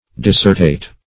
dissertate - definition of dissertate - synonyms, pronunciation, spelling from Free Dictionary
Dissertate \Dis"ser*tate\, v. i. [L. dissertatus, p. p. of